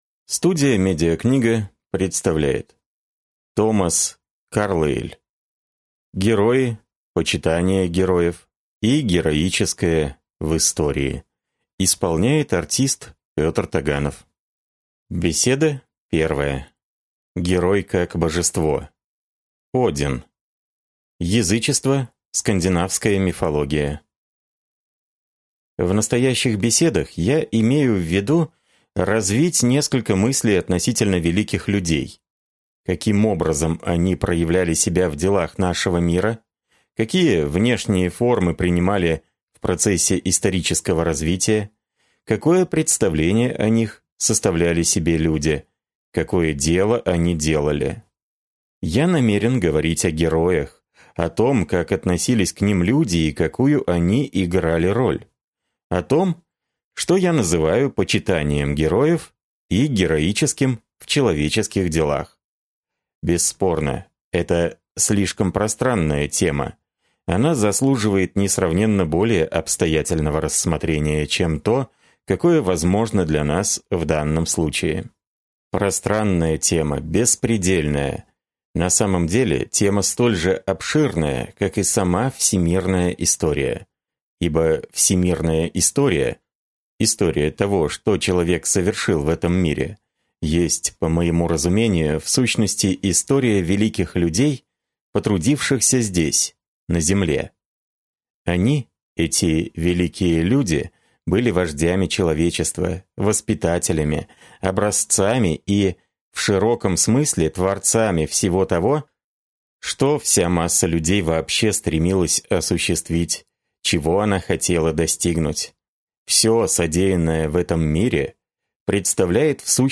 Аудиокнига Герои, почитание героев и героическое в истории | Библиотека аудиокниг